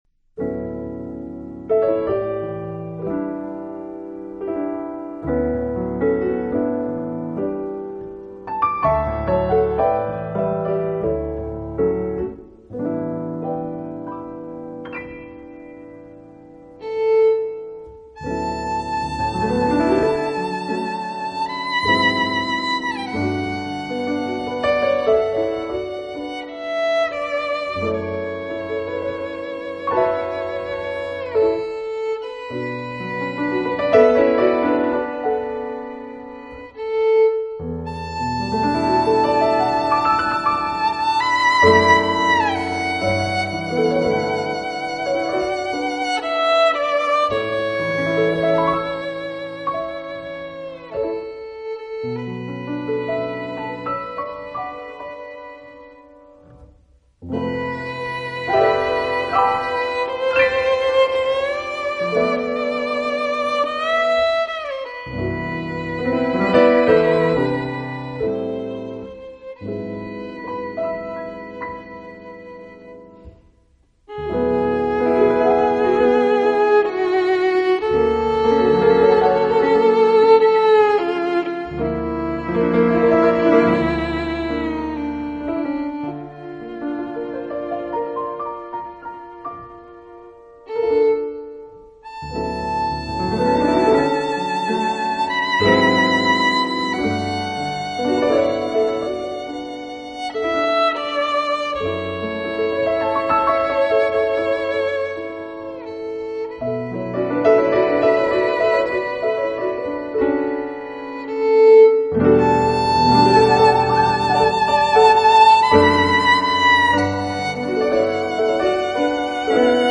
时而热情洋溢时而罗曼蒂克的钢琴，碰上婉约又高雅的小提琴会激出何种火花？